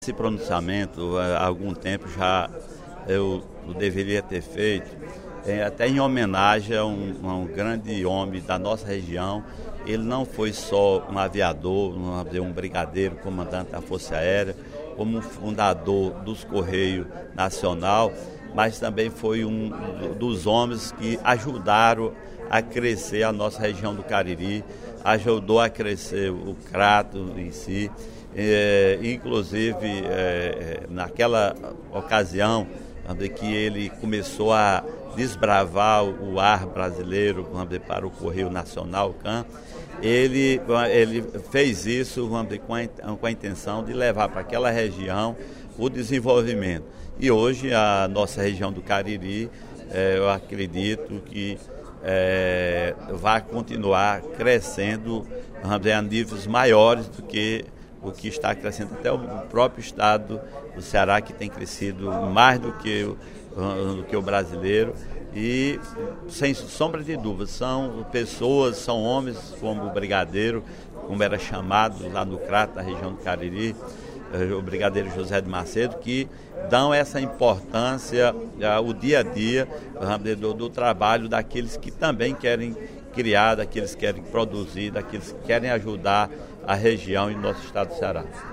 No primeiro expediente da sessão plenária da Assembleia Legislativa desta terça-feira (11/03), o deputado Sineval Roque (Pros) fez homenagem ao major-brigadeiro do ar José Sampaio Macedo, nascido no Crato, região do Cariri, em 17 de outubro de 1906.